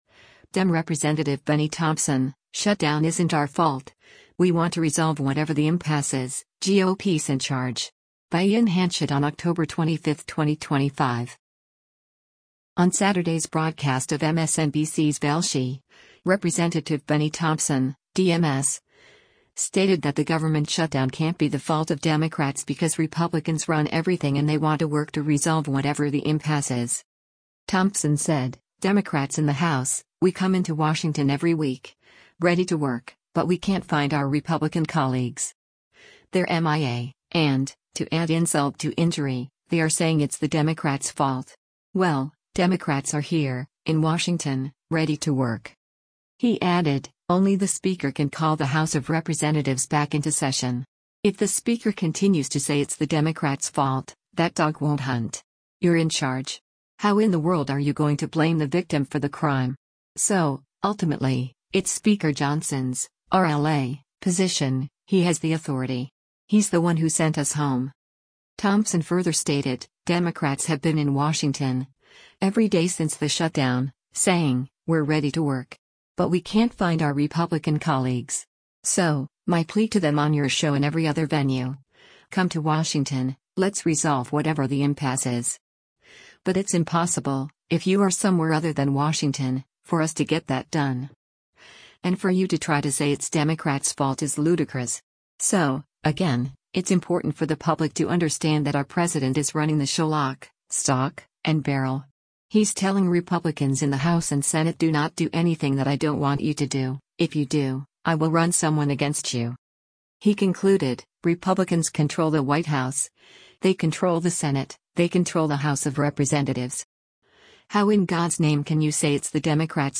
Video Source: MSNBC
On Saturday’s broadcast of MSNBC’s “Velshi,” Rep. Bennie Thompson (D-MS) stated that the government shutdown can’t be the fault of Democrats because Republicans “run everything” and they want to work to “resolve whatever the impasse is.”